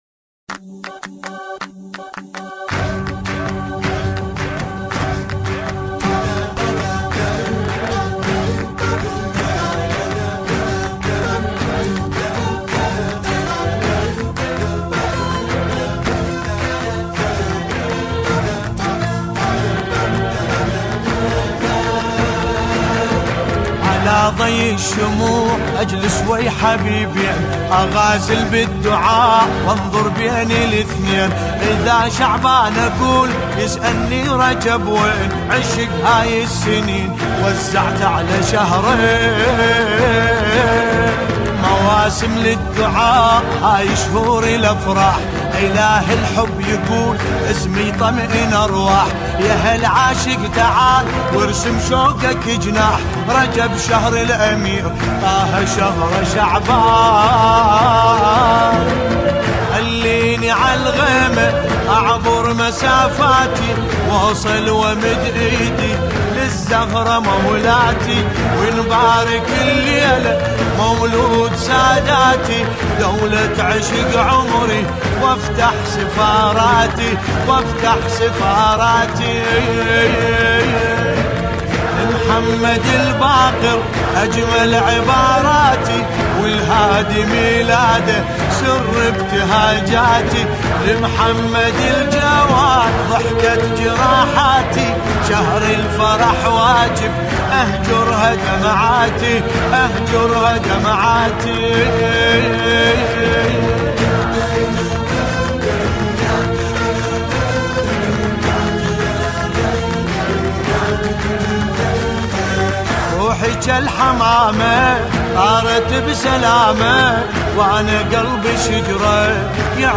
نماهنگ زیبای عربی